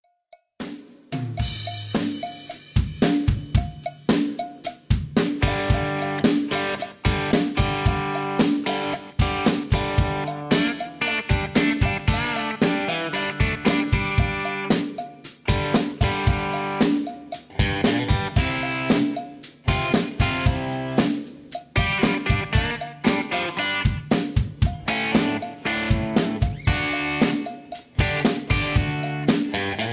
Tags: studio tracks , sound tracks , backing tracks , rock